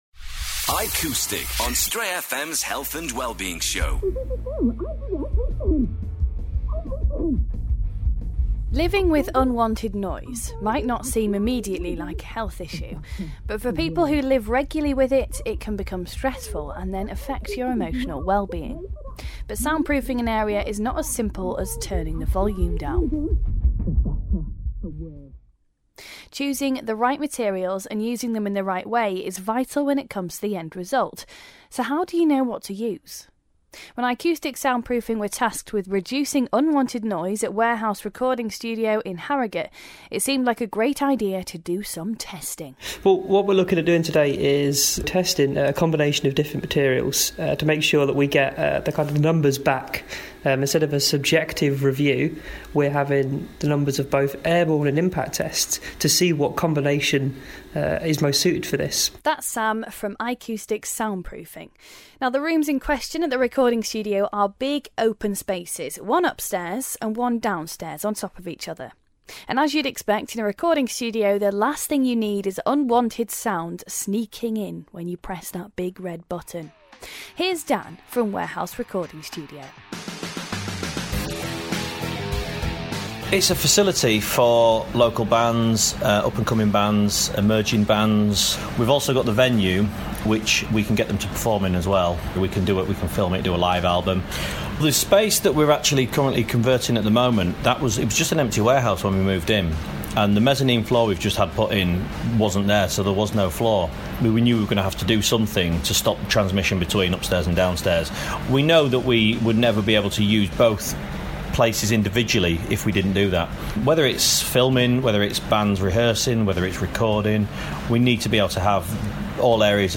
We hear to a music recording studio to hear how sound can be tested (and reduced!) to make an environment quieter with Ikoustic Sound Proofing.